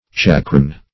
Checkrein \Check"rein`\, n.